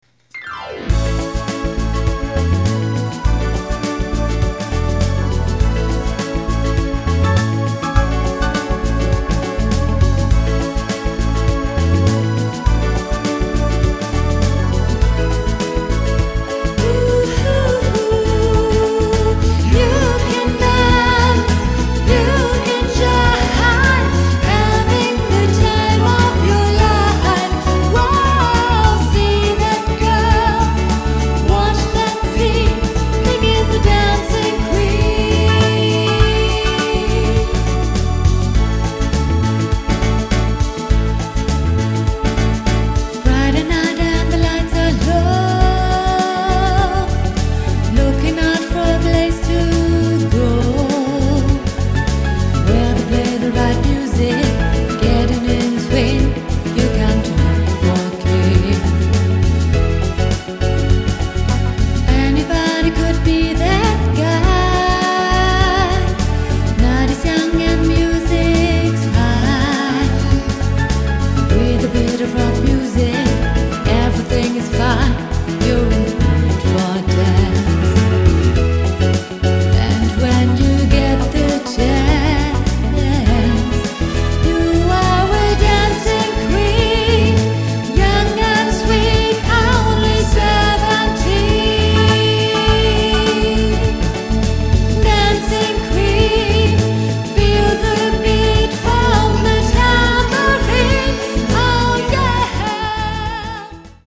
Tanz-& Unterhaltungskapelle